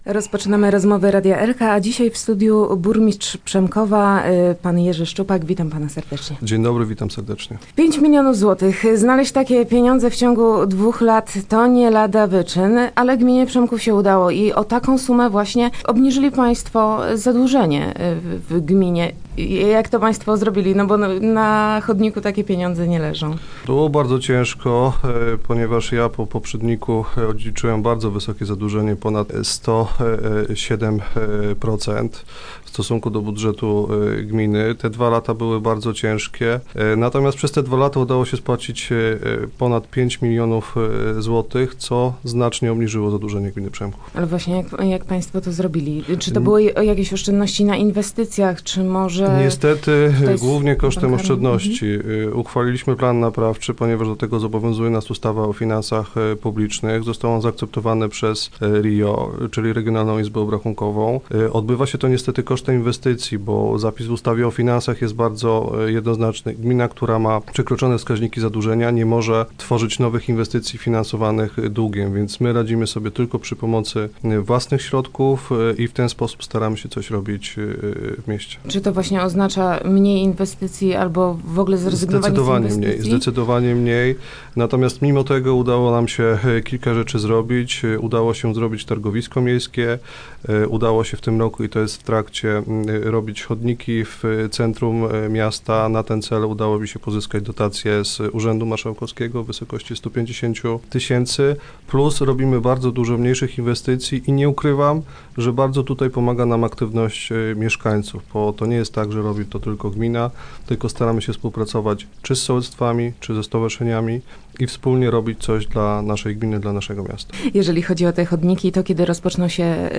Dziś w studiu Radia Elka gościliśmy burmistrza Jerzego Szczupaka, który opowiedział nam o walce samorządu z długami, a także o planach przejęcia wodociągów i zadaniach na przyszły rok.